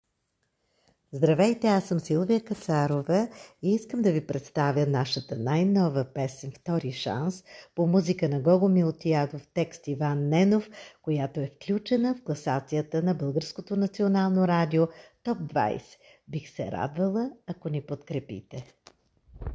Повече за песента “Втори шанс” чуйте от Силвия Кацарова в интервю за слушателите на БНР Топ 20: